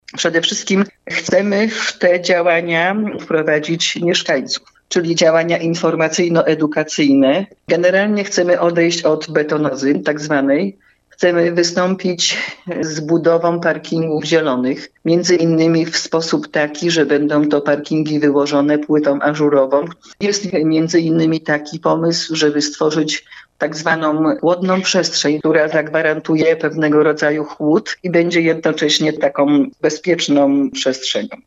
Burmistrz Barlinka Bernarda Lewandowska, w rozmowie z Twoim radiem, wyjaśniła dlaczego magistrat uważa, że taka adaptacja do zmian klimatu jest konieczna?